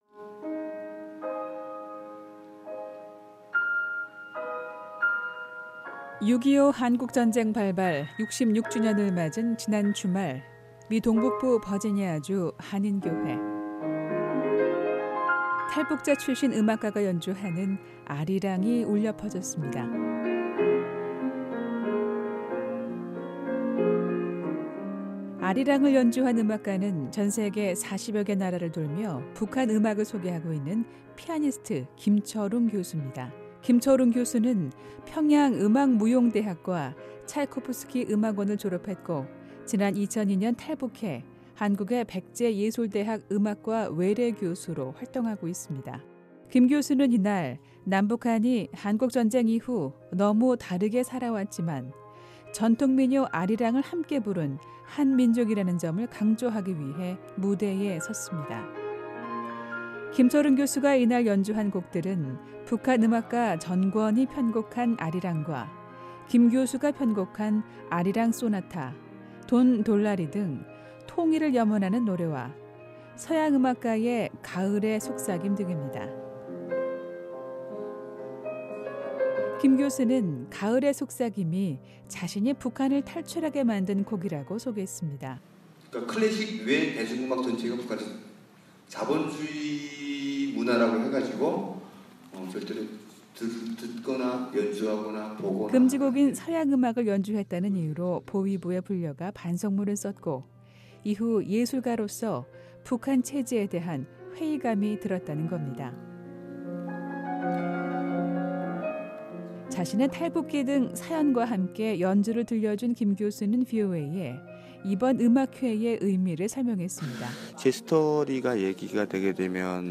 탈북자 출신 피아니스트가 미국 수도 워싱턴 인근에서 통일을 주제로 한 이야기 음악회를 열었습니다.